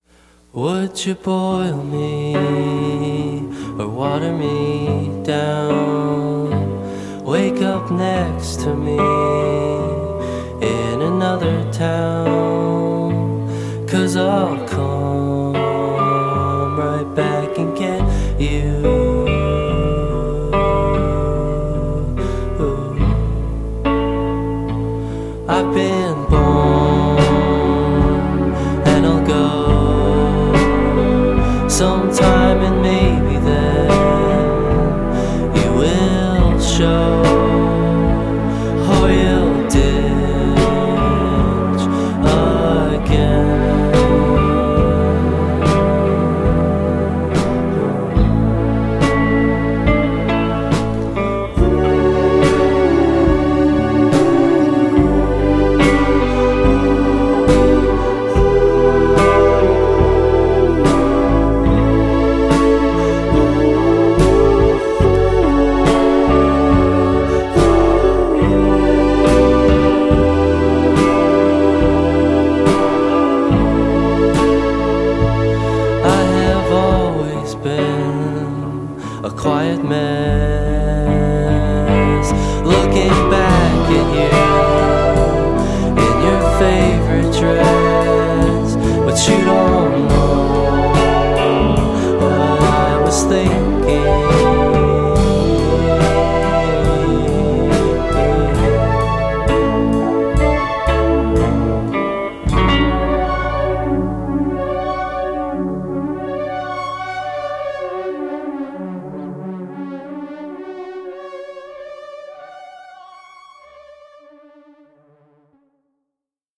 In ogni caso niente di facile, pochi compromessi.